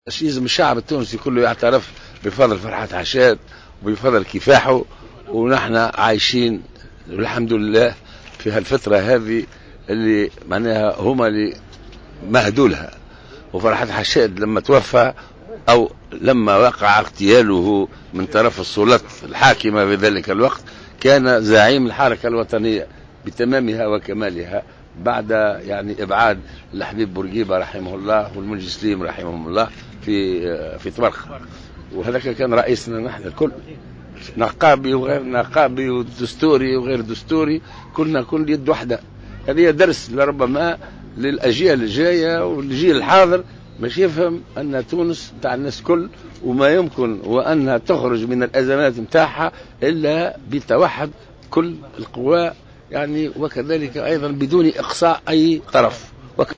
قال الباجي قايد السبسي على هامش احياء ذكرى اغتيال الزعيم النقابي فرحات حشاد إن مسيرته تعد درسا للأجيال القادمة لتفهم أن تونس للجميع ولايمكن ان تخرج من أزماتها الا بتوحد قواها دون اقصاء أي طرف مؤكدا ان الزعيم فرحات حشاد وحد الصفوف ووحد التونسيين عندما تم ابعاد الحبيب بورقيبة والمنجي سليم زمن الإستعمار وكان رئيسا للجميع على حد قوله.